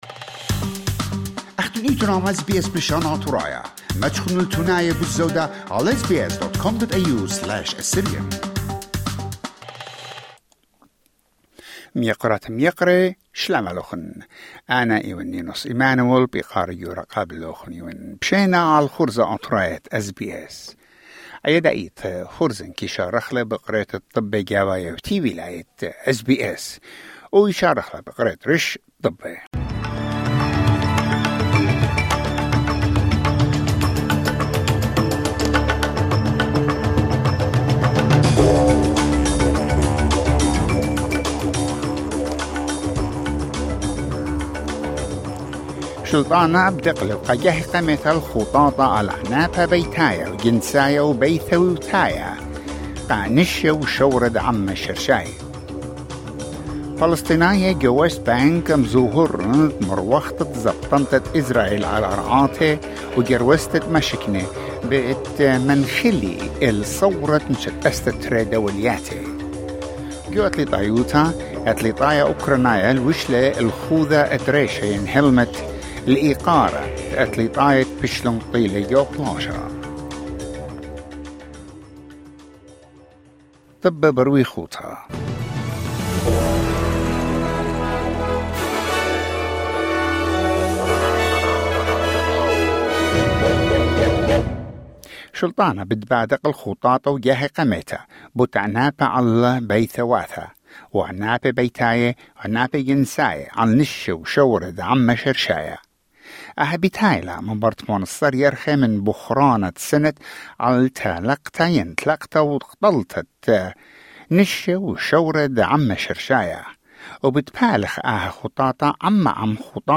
News bulletin 10 February 2026